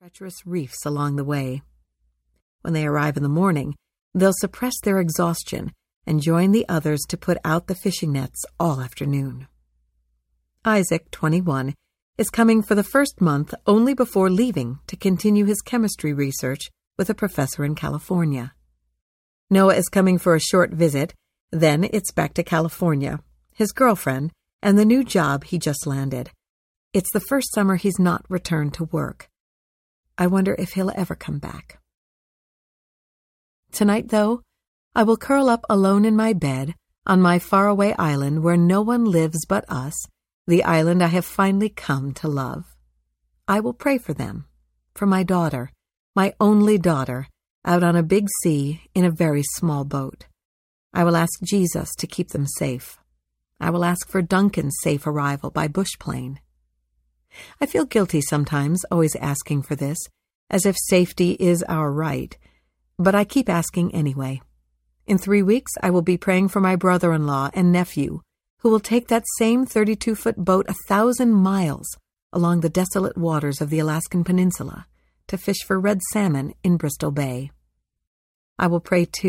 Crossing the Waters Audiobook
Narrator
6.22 Hrs. – Unabridged